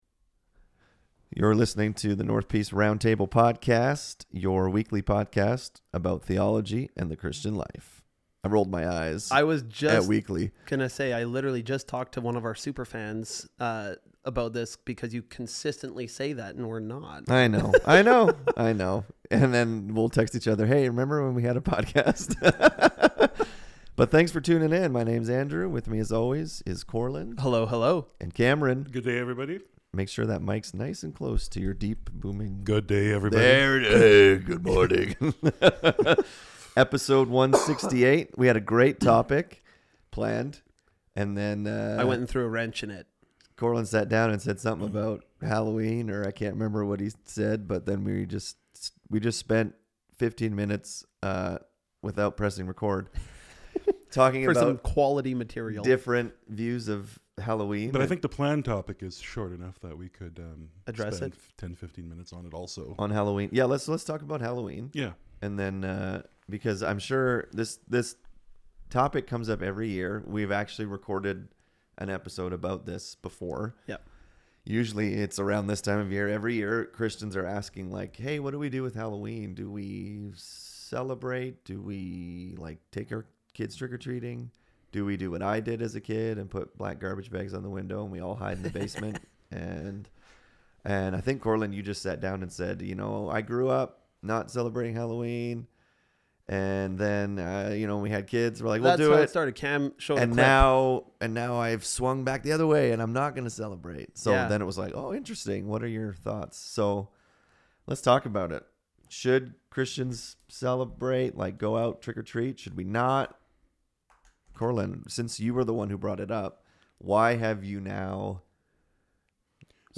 In this episode the guys talk all things Halloween. What should a Christians response be to this holiday?